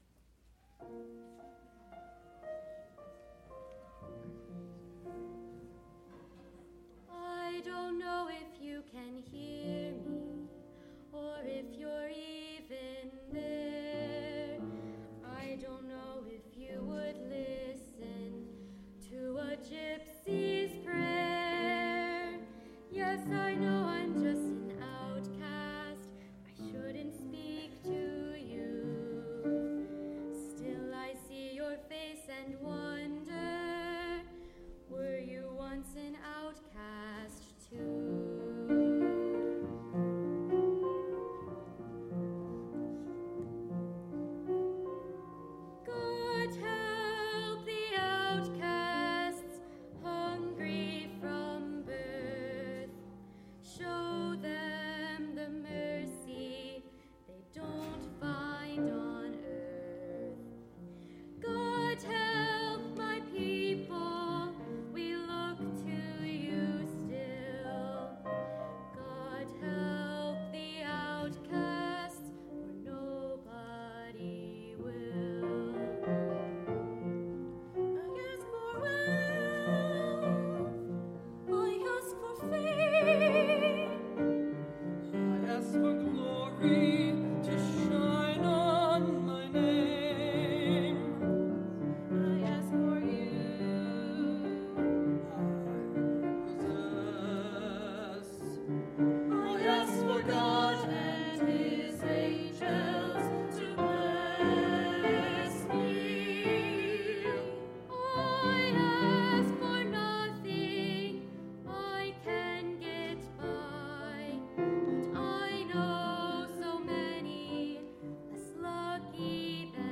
June 12, 2016 Offertory Anthem
Choir and Orchestra